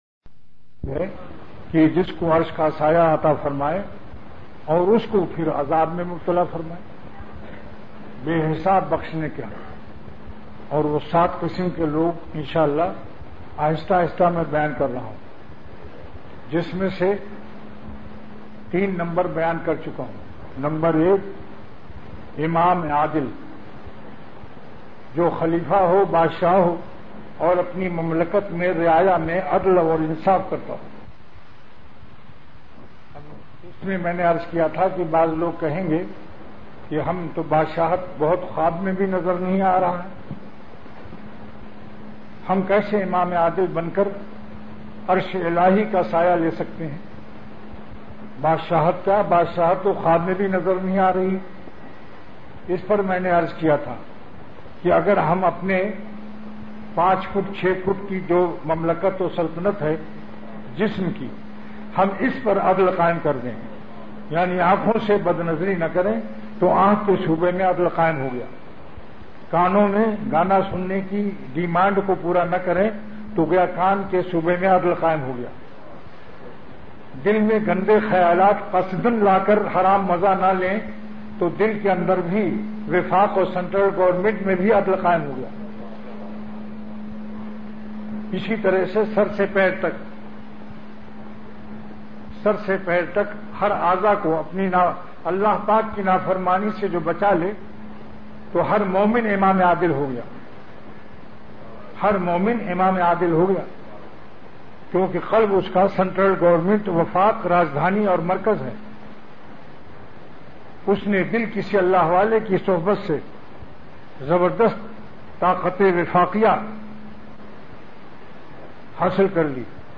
وعظ کے اختتام پر درد بھری دُعا روتے ہوئے فرمائی۔